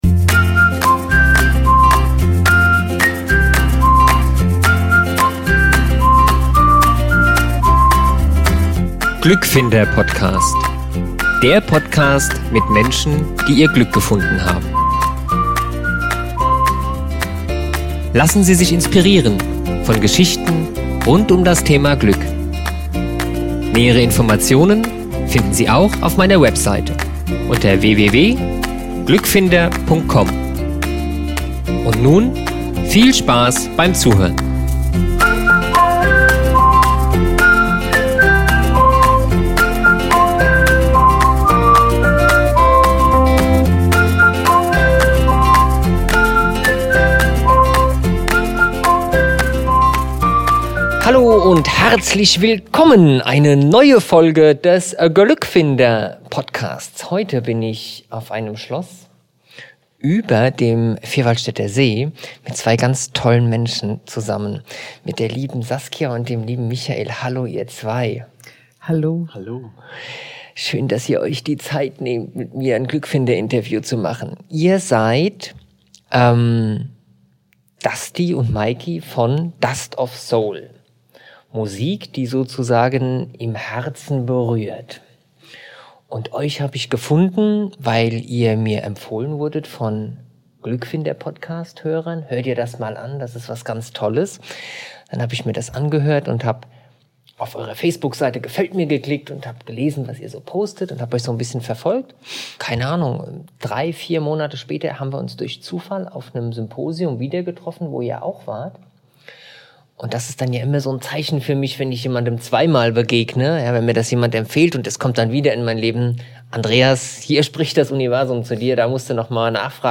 In dieser Doppelfolge des Glückfinder Podcasts führe ich zwei Interviews mit zwei wunderbare Menschen und spreche mit ihnen über ihren Weg zum Glück.